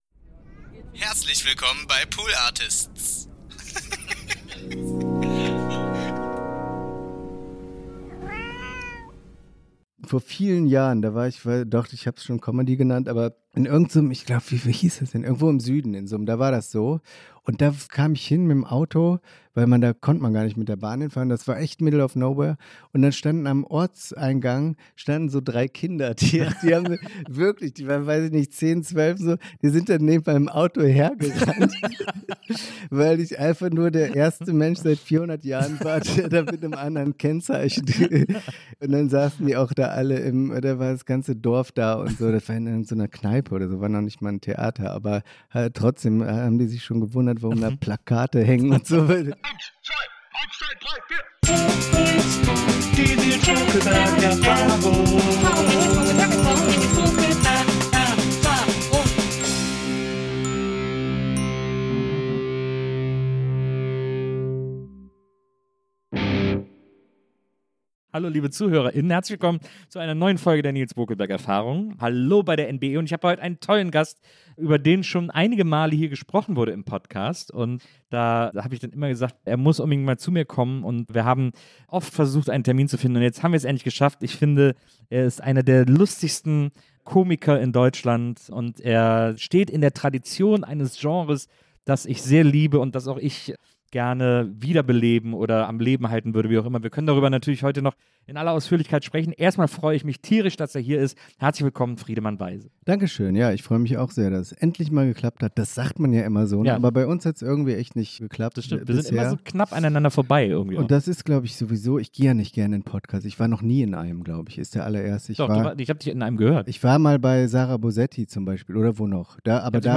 Herzlich Willkommen im Wohnzimmerstudio von Nilz Bokelberg! Eingeladen sind all die Menschen, mit denen Nilz schon immer sprechen wollte. Diese Begegnungen folgen keiner Agenda, der Notizblock kann auch mal fliegen und schwungvolle Abzweigungen sind äußerst erwünscht: vom ungefährlichen Dreiviertelwissen über die Vorliebe für Freizeitparks bis hin zu emotionalen Gesangseinlagen.